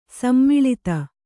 ♪ sammaḷita